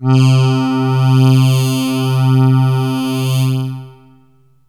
AMBIENT ATMOSPHERES-3 0004.wav